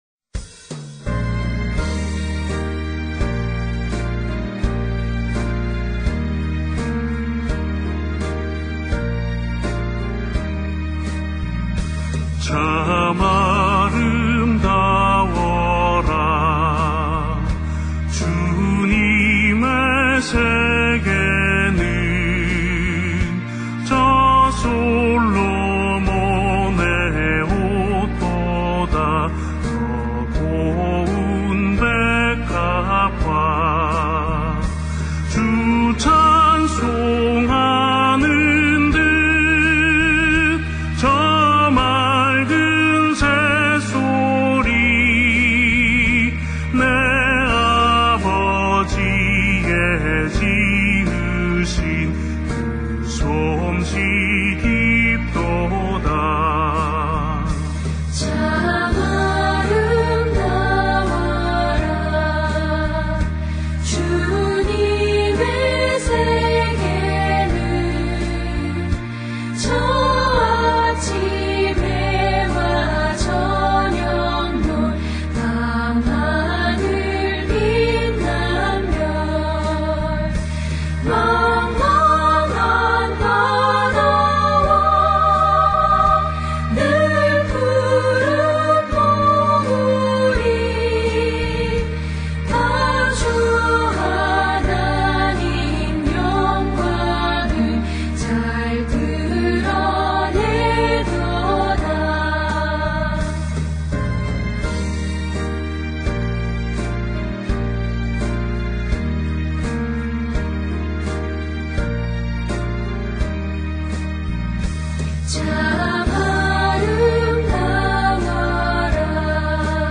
새찬송가: 478(통78)